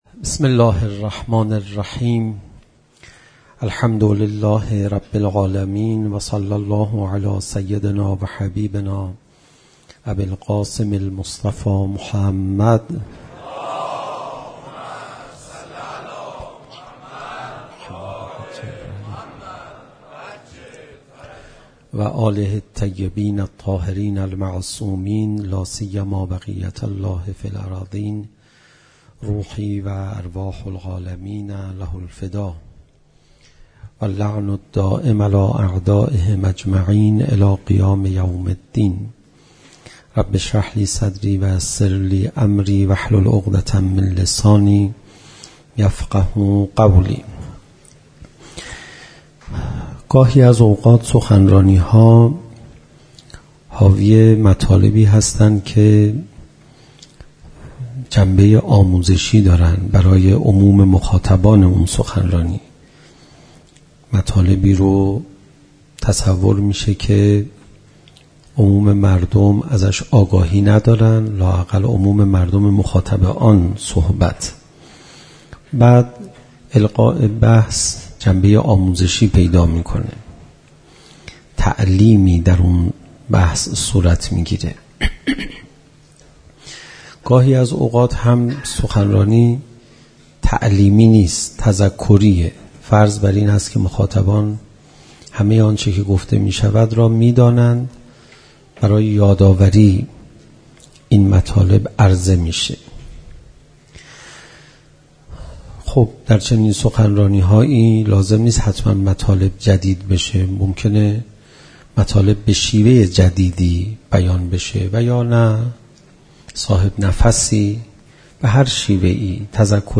سخنرانی حجت‌الاسلام پناهیان شب اول محرم الحرام 1438 صوت - تسنیم
صوت سخنرانی حجت‌الاسلام پناهیان شب اول محرم درمیثاق با شهدای دانشگاه امام صادق (ع) را در تسنیم گوش کنید.